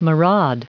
Prononciation du mot maraud en anglais (fichier audio)
Prononciation du mot : maraud